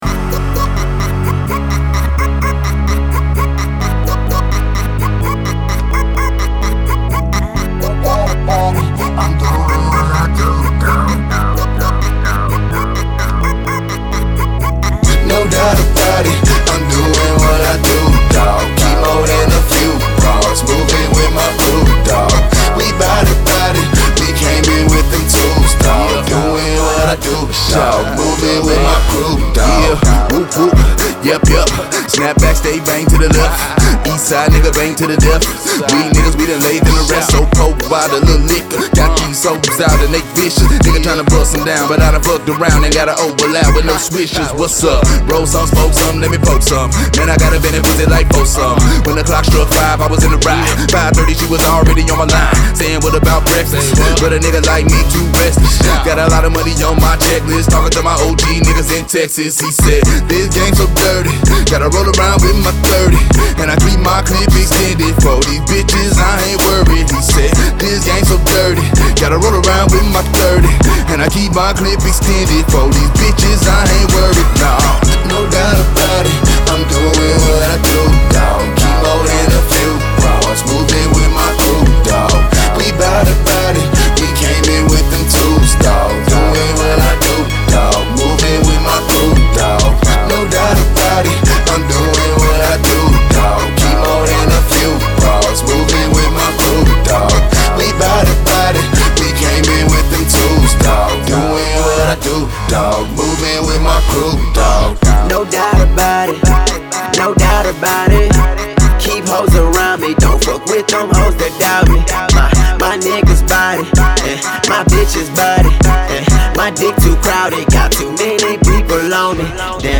autotuned-tinged verse
dope riding song